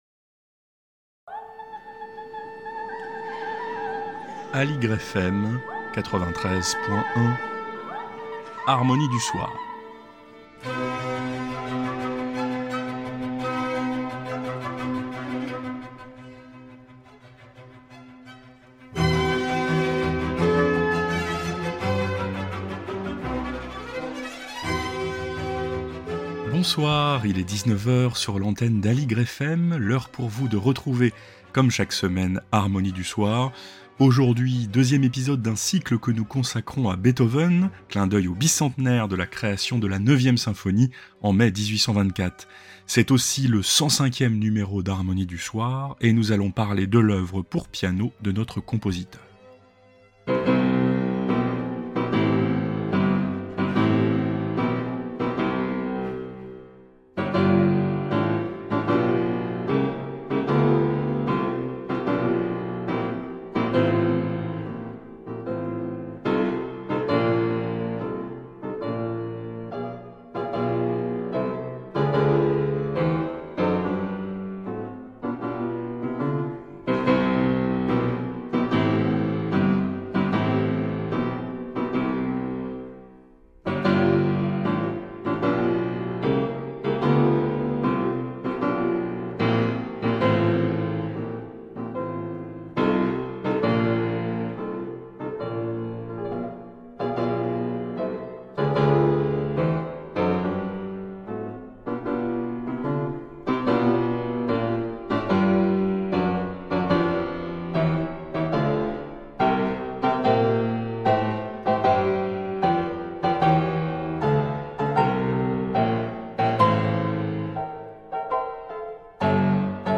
Harmonie du soir # 105 - Beethoven épisode 2 : les sonates pour piano